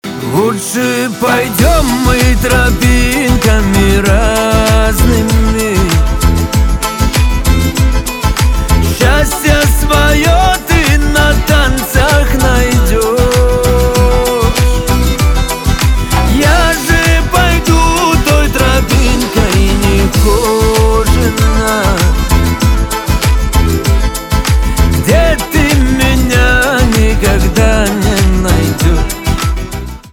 кавказские
грустные , печальные
чувственные
гитара , барабаны